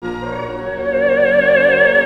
Extraction of a Vibrato from Orchestral Background
A similar (and still very raw) approach can be used for extracting a vibrato singing voice from an orchestral background.